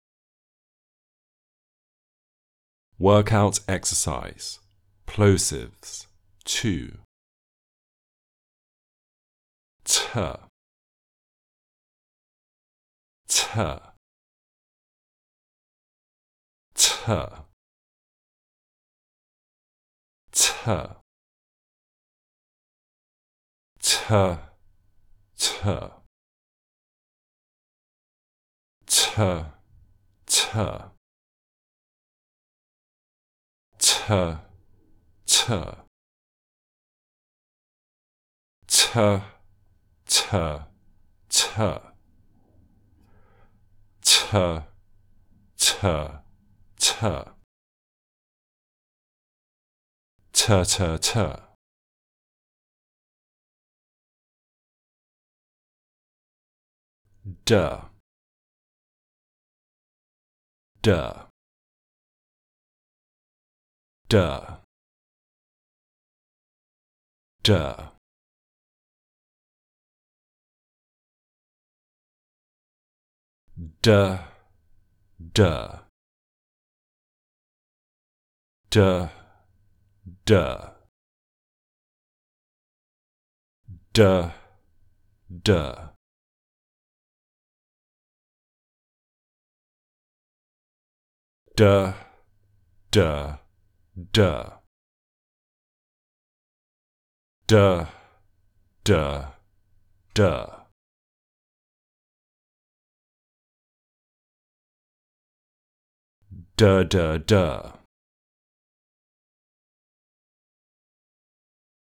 The Basic Building Blocks of Speech - Level 01 - British English Pronunciation RP Online Courses
Plosives 02
02_plosive_02.mp3